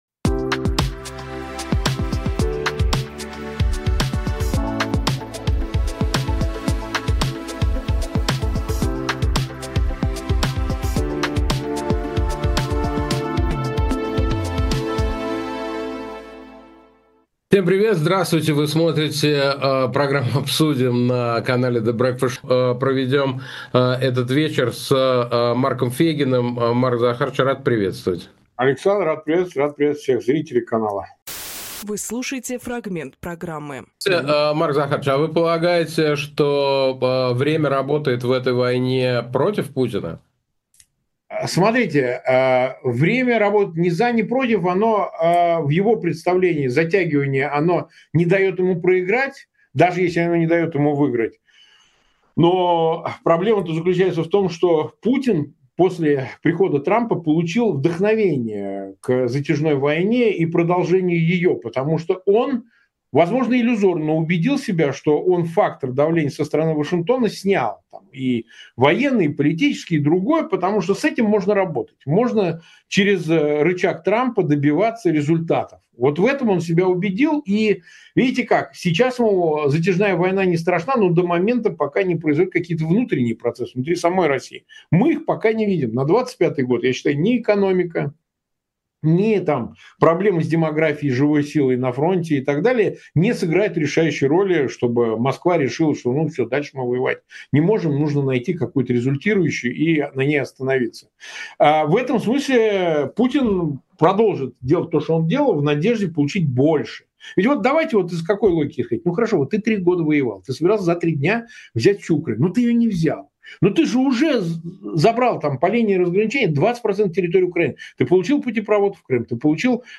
Фрагмент эфира от 02.06